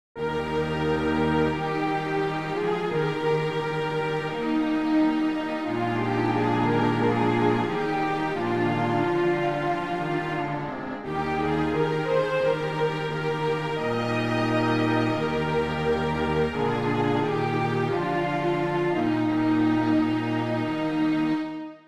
キーE♭ショート版